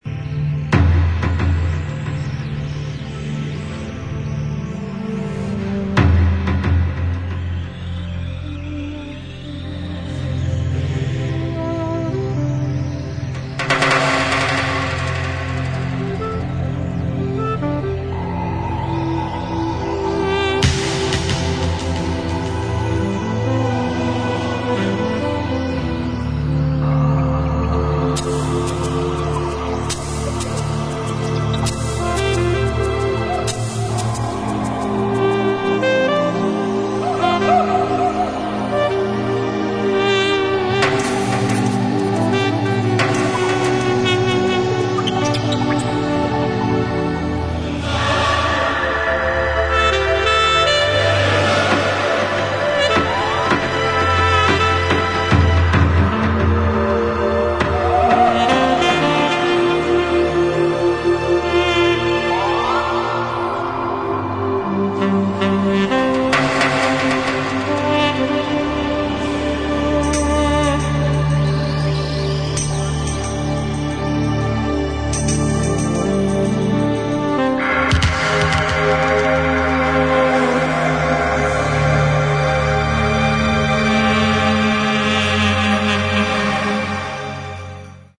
シンセやパーカッション、ギターなどがとろけるように絡み合う、正にバレアリックな最高のアルバム！